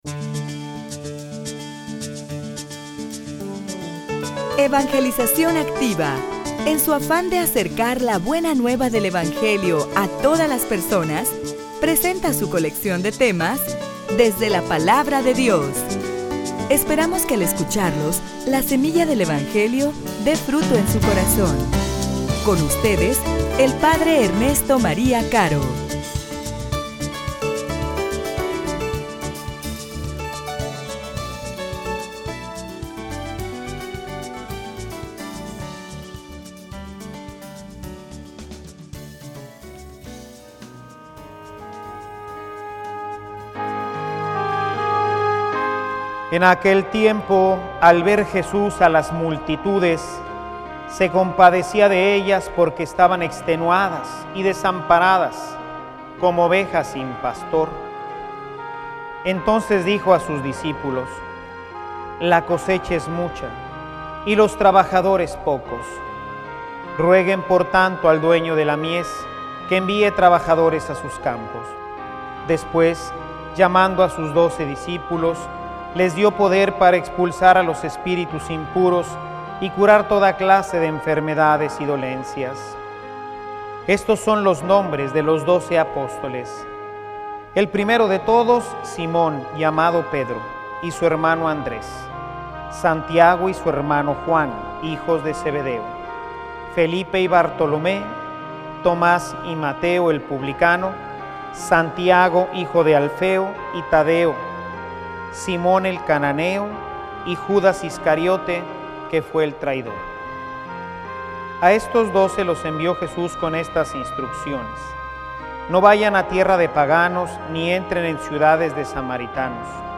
homilia_La_mies_es_mucha.mp3